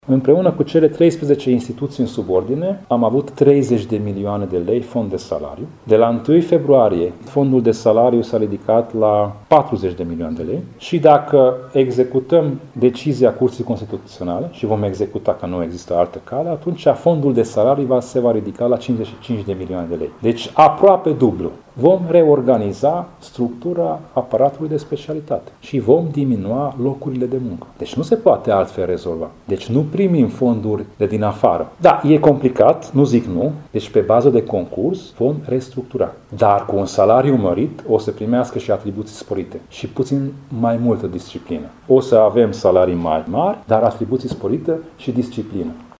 Majorarea salariilor din administraţie va atrage după sine restructurări şi disponibilizări de personal în cadrul Consiliului Judeţean Covasna, a declarat preşedintele instituţiei, Tamas Sandor.